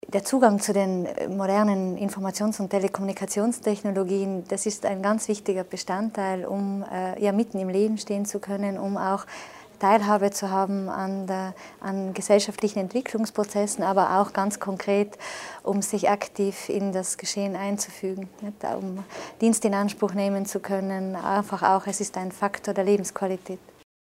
Landesrätin Martha Stocker zum Wert des Projekts "Senioren online"